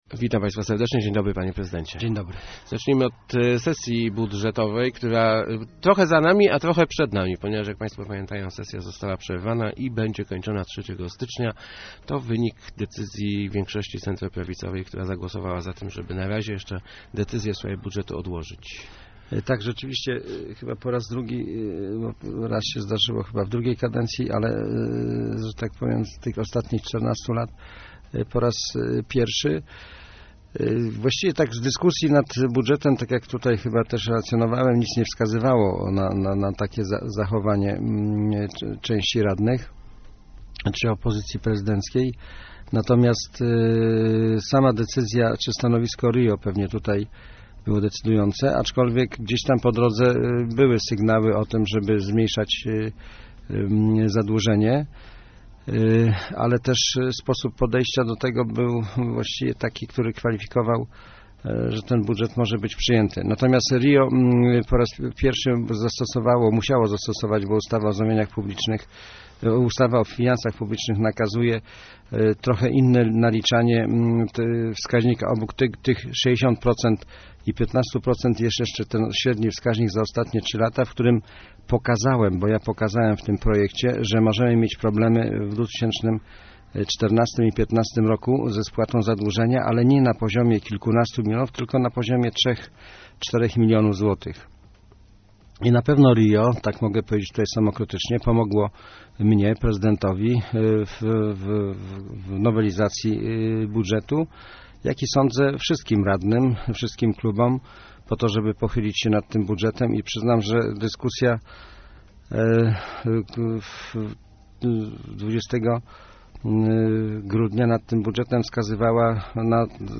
Gościem Kwadransa był prezydent Tomasz Malepszy ...